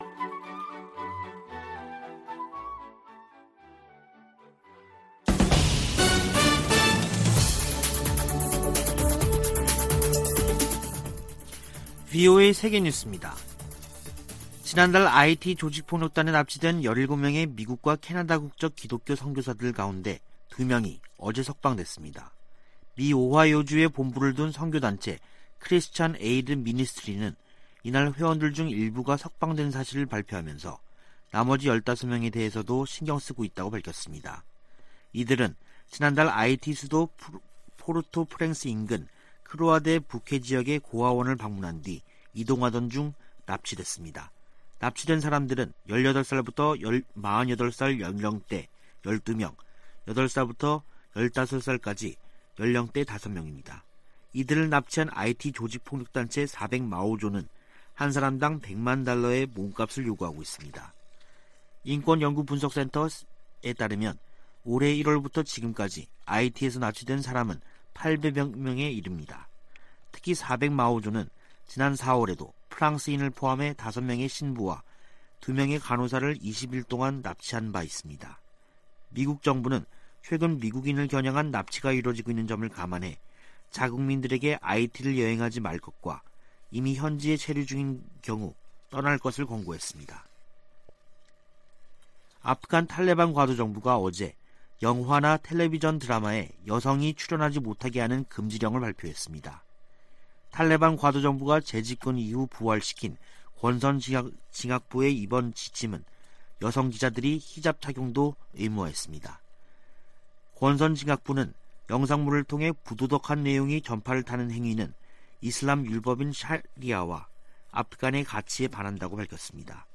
VOA 한국어 간판 뉴스 프로그램 '뉴스 투데이', 2021년 11월 22일 2부 방송입니다. 바이든 미국 대통령의 베이징 동계올림픽 외교적 보이콧 검토에 따라 한국 정부의 한반도 평화프로세스 구상에 어떤 영향이 미칠지 주목되고 있습니다.